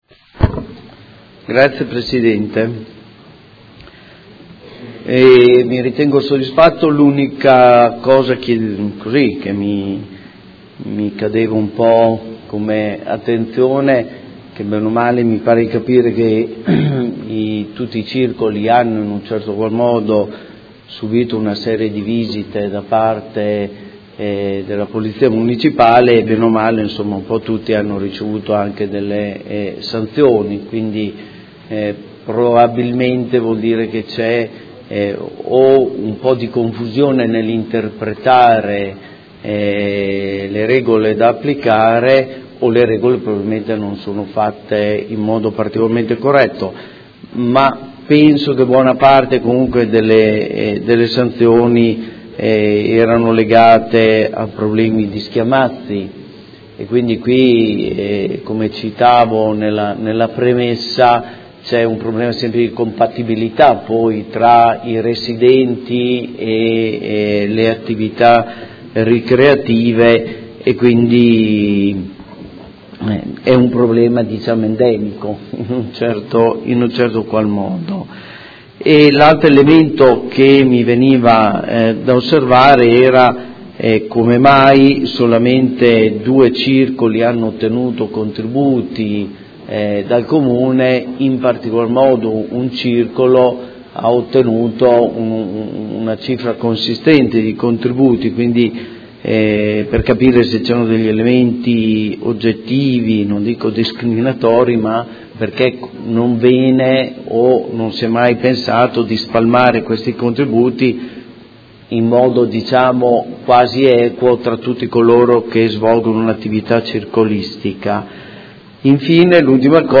Seduta del 2/2/2017. Interrogazione del Consigliere Montanini (CambiAMOdena) avente per oggetto: Circoli privati/Locali Pubblici – Rispetto normative di pubblica sicurezza – Rapporti con il Comune di Modena – Istituzione di un osservatorio. Replica